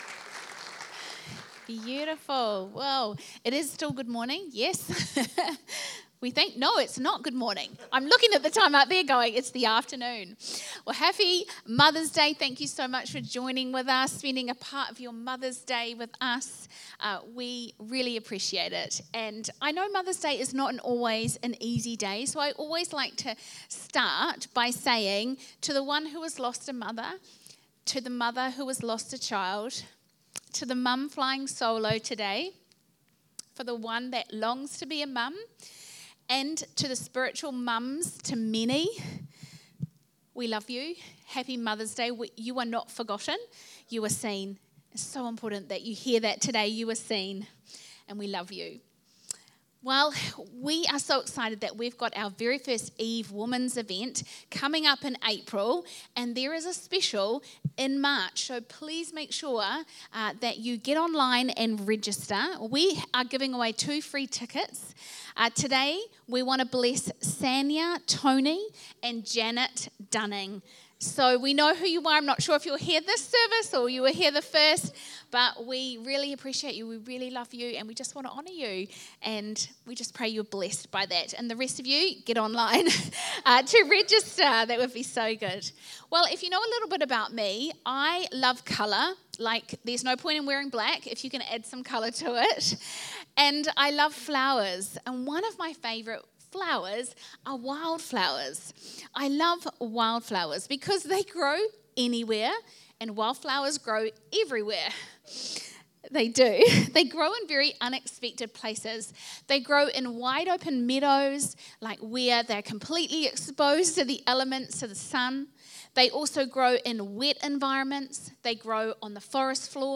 Sunday Messages Where The Wildflowers Grow